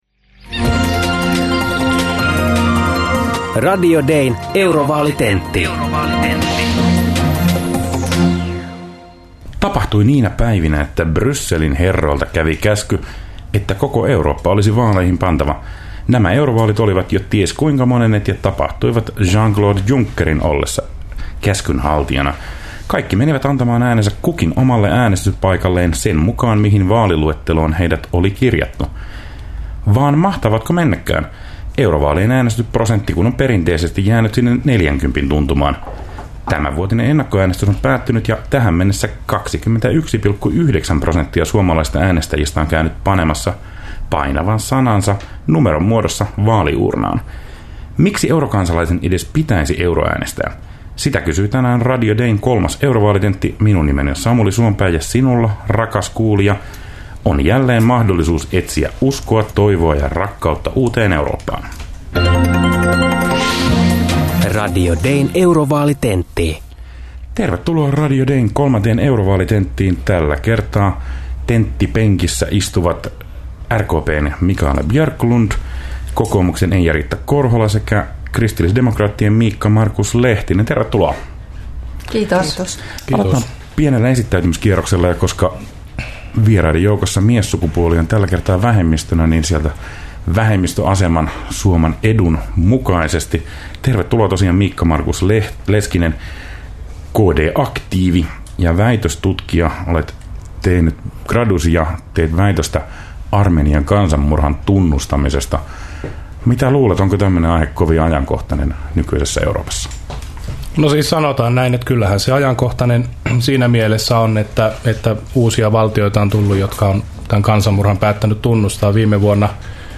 Vaalitentit kuullaan Radio Dein taajuuksilla keskiviikkoaamuisin yhdeksän uutisten jälkeen suorana lähetyksenä 8. toukokuuta alkaen.